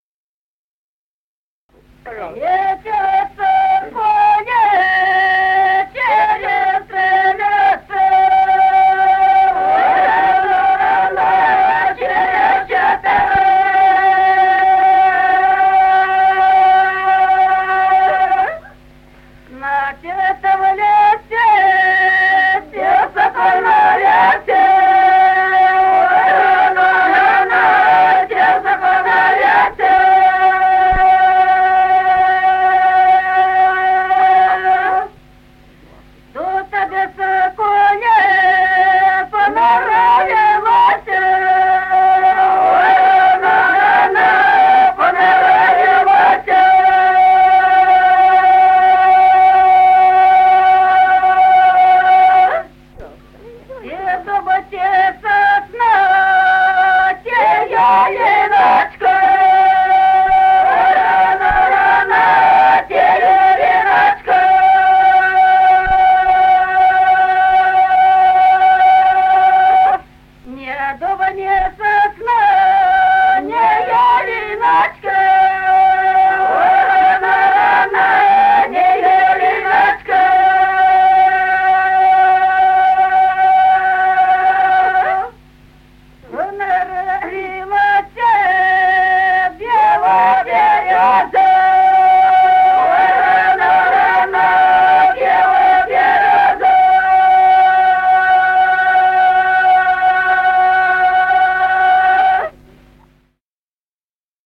Народные песни Стародубского района «Летел соколик», свадебная.
запев
подголосник
с. Остроглядово.